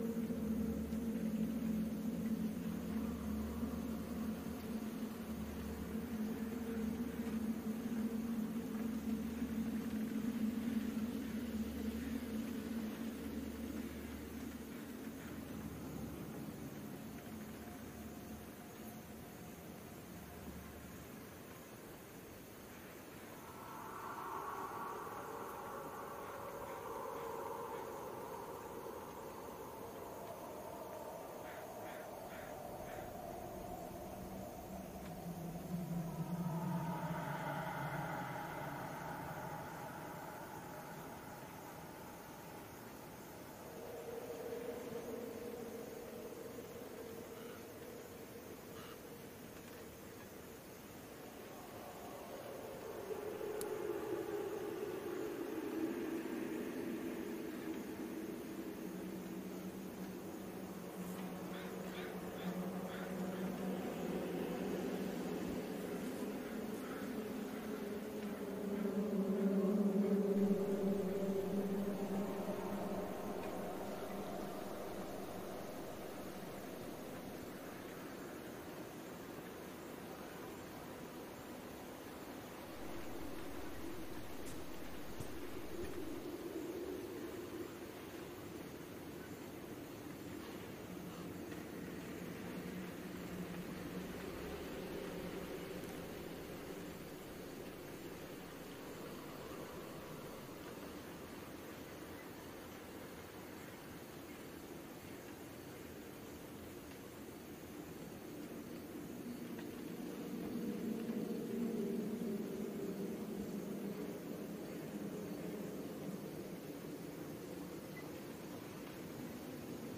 白噪声诡异室外.wav